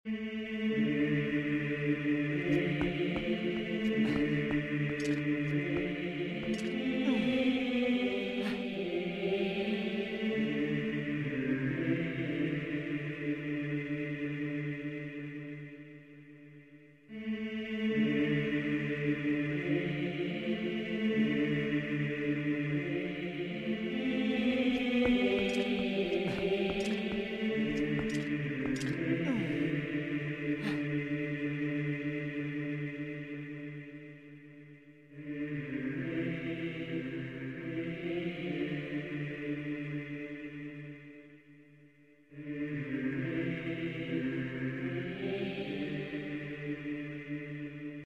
The most eerie but beautiful BGM